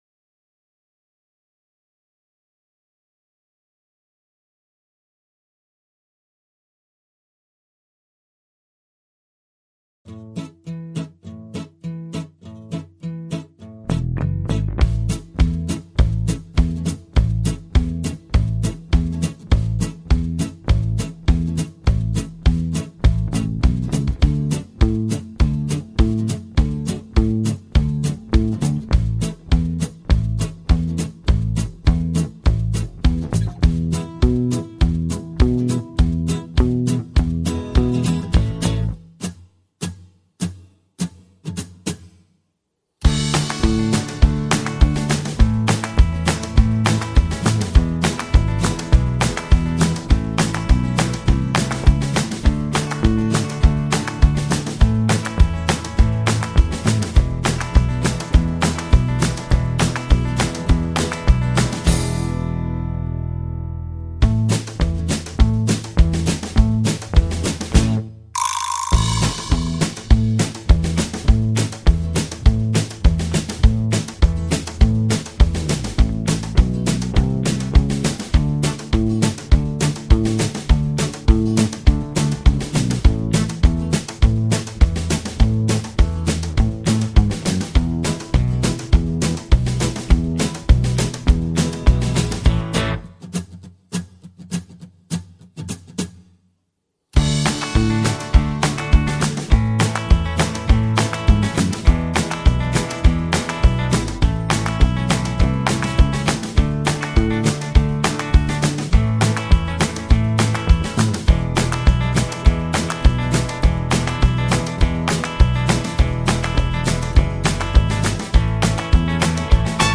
(Without Backing Vocals)